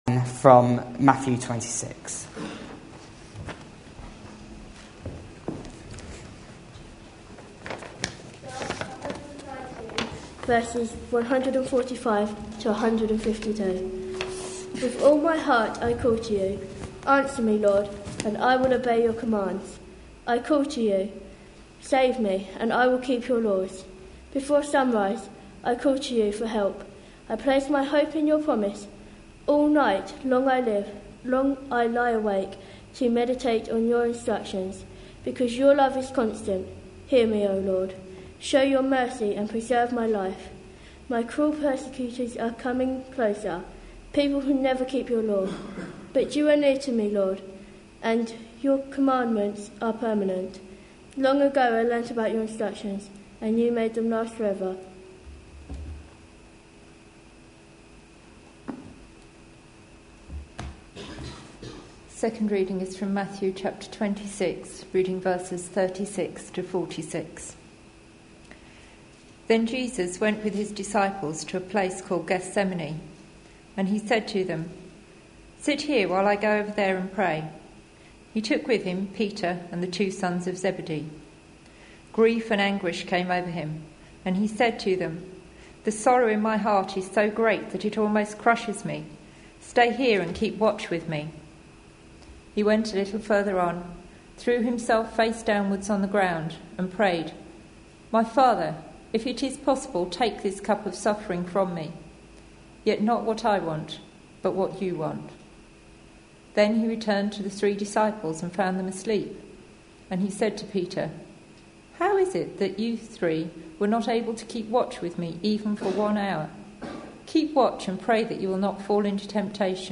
A sermon preached on 24th February, 2013, as part of our Passion Profiles and Places -- Lent 2013. series.